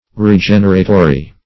Search Result for " regeneratory" : The Collaborative International Dictionary of English v.0.48: Regeneratory \Re*gen"er*a*to*ry\ (-?*t?*r?), a. Having power to renew; tending to reproduce; regenerating.